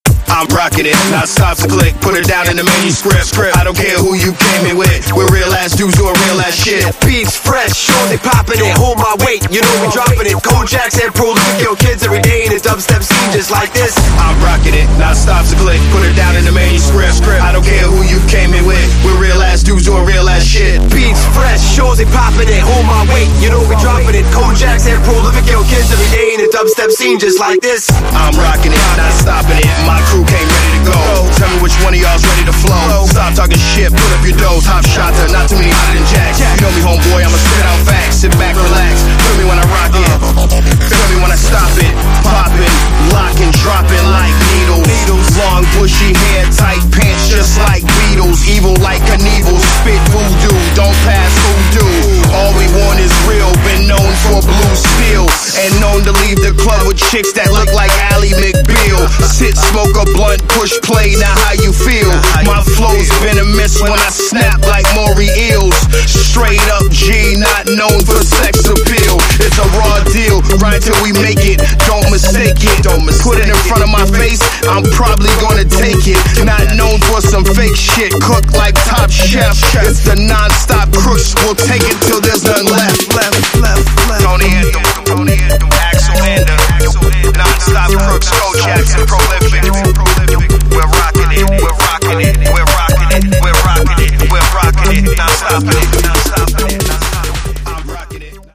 Styl: Dub/Dubstep Vyd�no